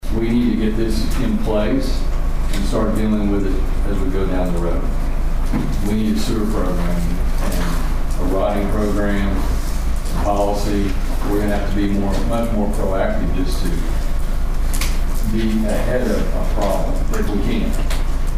The Nowata City Commissioners met for a regularly scheduled meeting on Monday evening at the Nowata Fire Department.
City Attorney John Heskett talked about the importance of the sewer change.